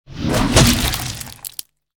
dragonclaw.ogg